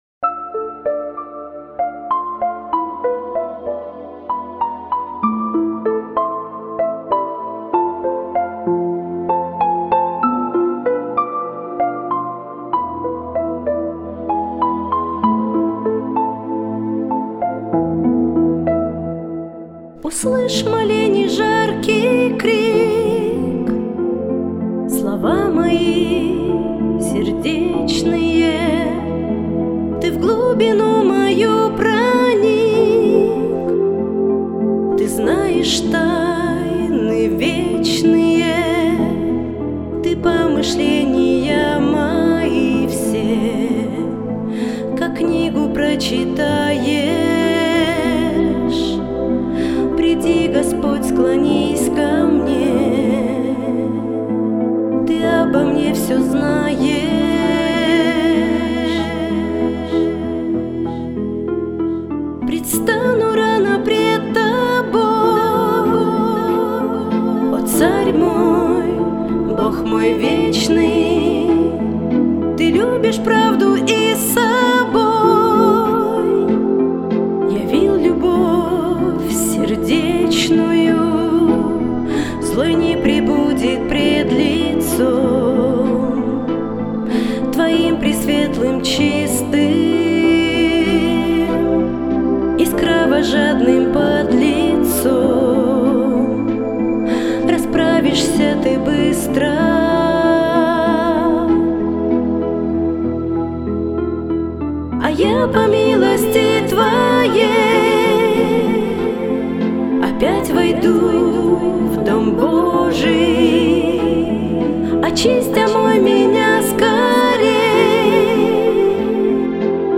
песня
169 просмотров 290 прослушиваний 11 скачиваний BPM: 75